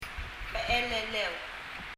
becheleleu[bɛ ! ɛlələu]white